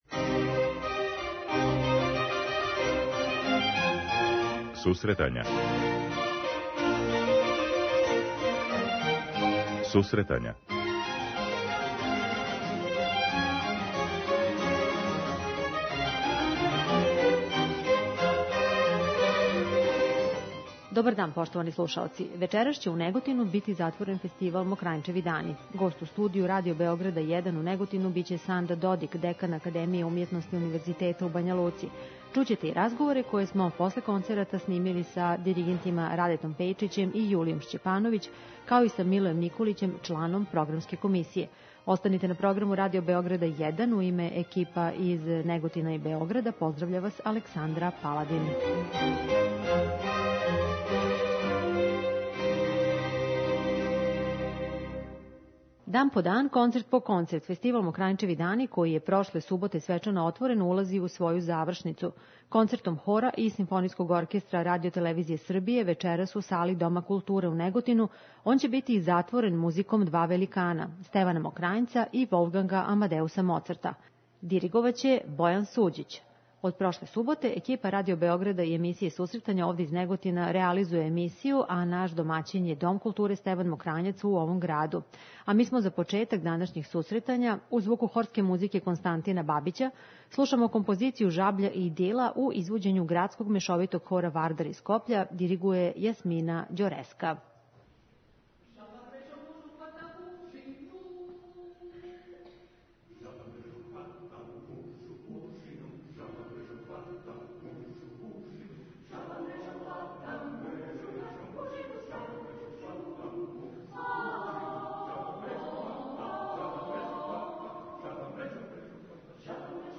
Вечерас ће у Неготину бити затворен фестивал „Мокрањчеви дани”.